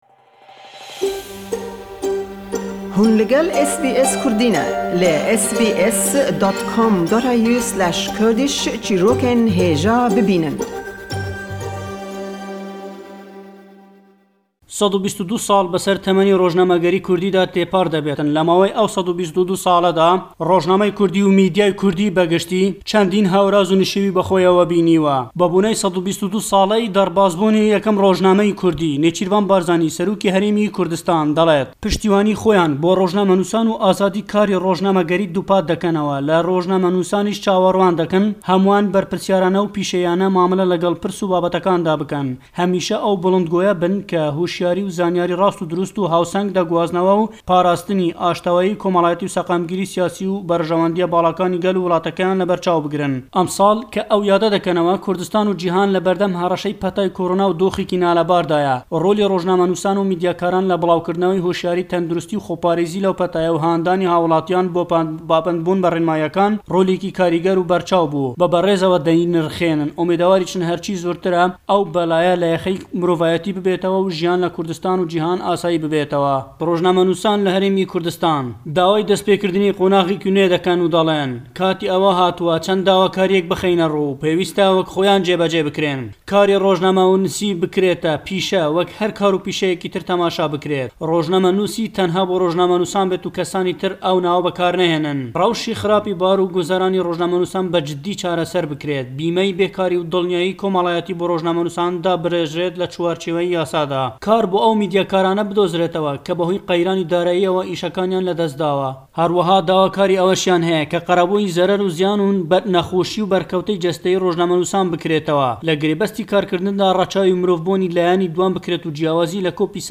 Le em raporte da le Hewlêre we, dawakaranî rojnamegeranî Kurdîstan bibîsta le sallyadî rojnamegerî Kurdî da.